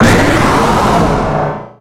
Cri de Méga-Tyranocif dans Pokémon X et Y.
Cri_0248_Méga_XY.ogg